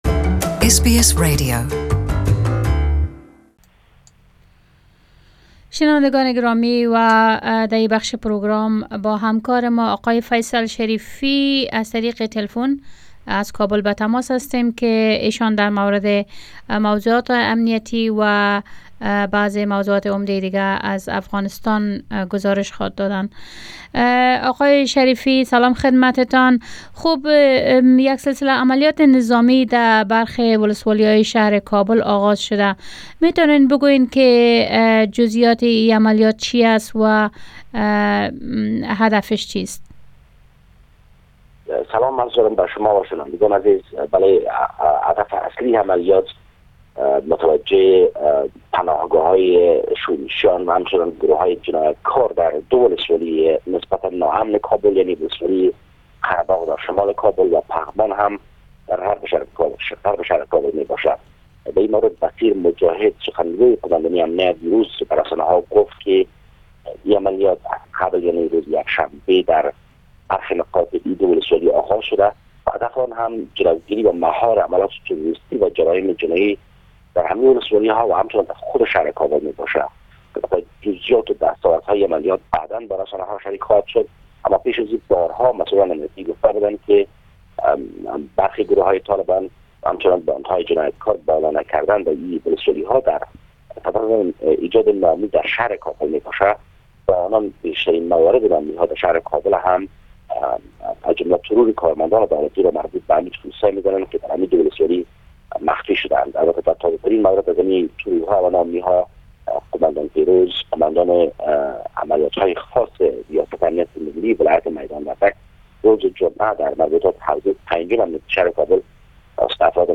Report from Afghanistan Tuseday 12/02